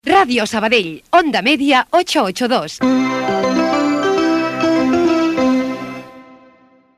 Indicatiu de l'emissora i freqüència